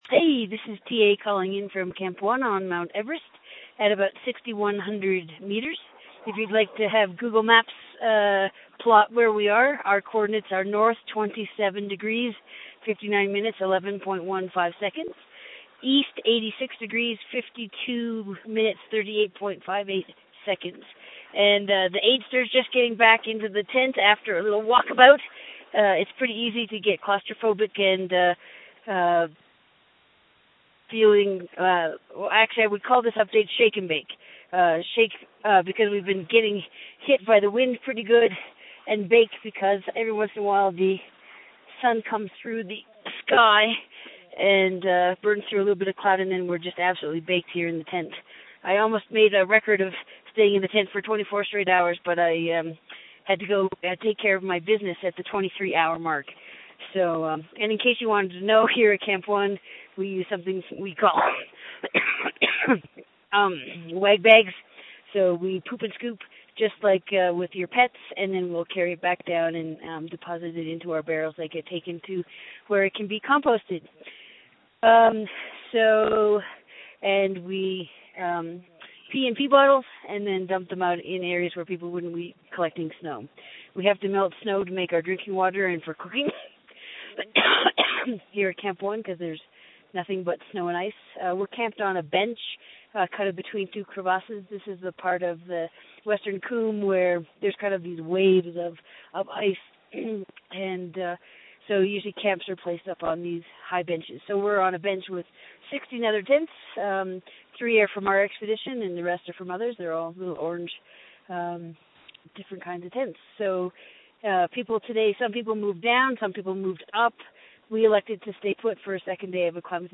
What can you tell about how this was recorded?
Audio Post Camp 1 – Everest 3.0 Day 20